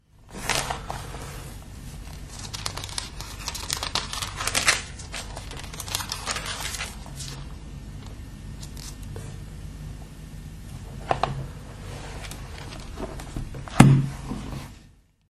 描述：翻开教会在1942年给我父亲的《圣经》（荷兰语译本）中的《路得》一书的页面。